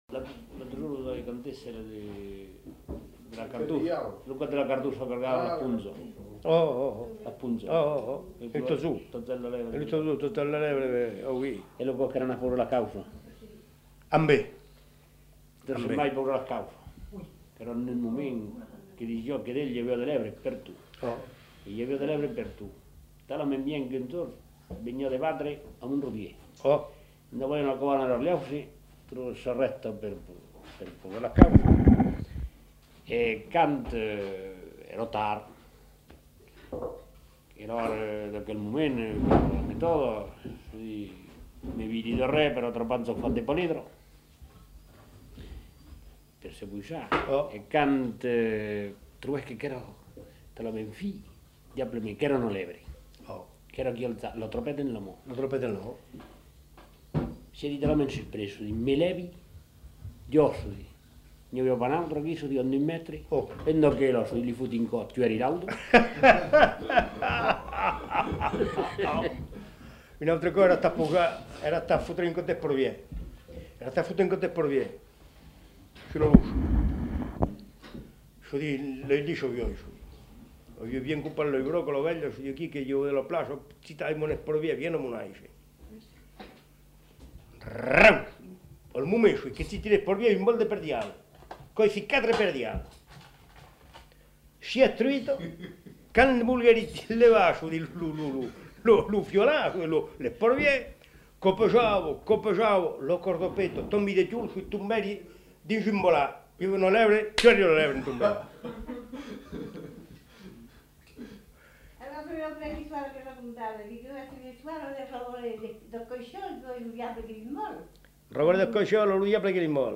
Aire culturelle : Périgord
Genre : conte-légende-récit
Effectif : 1
Type de voix : voix d'homme
Production du son : parlé